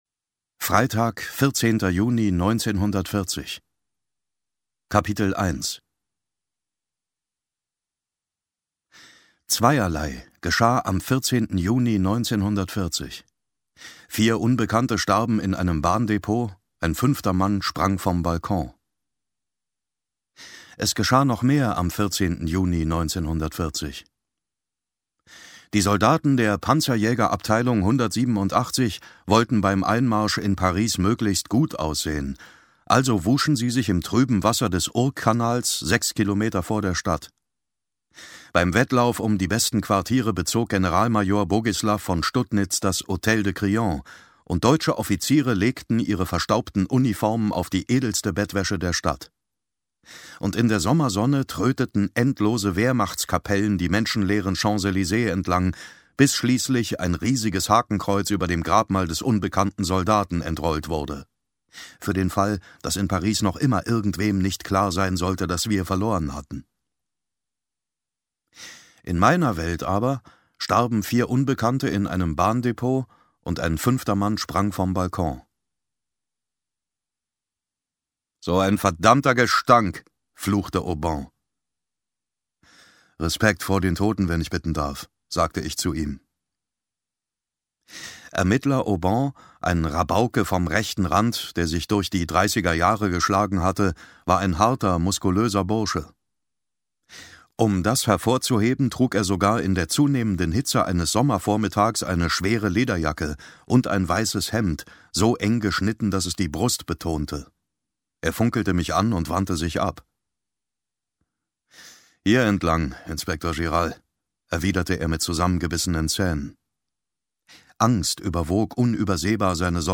David Nathan (Sprecher)
Ungekürzte Lesung